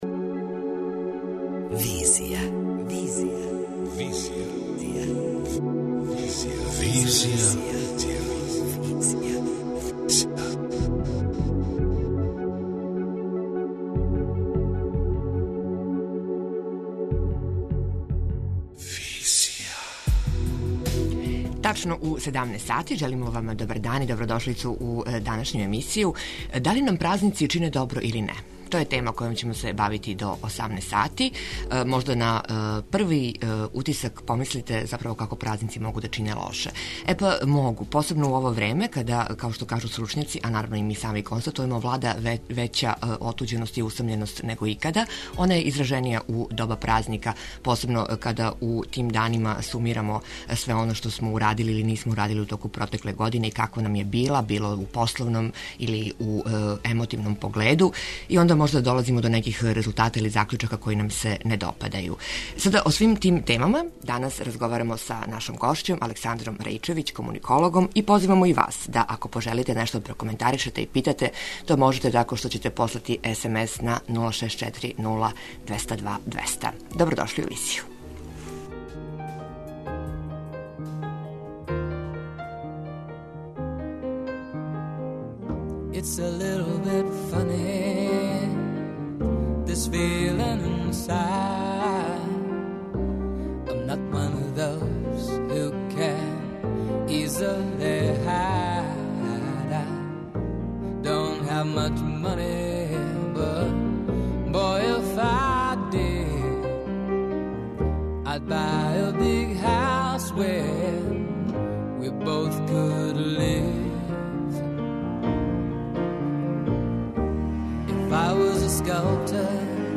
преузми : 26.91 MB Визија Autor: Београд 202 Социо-културолошки магазин, који прати савремене друштвене феномене.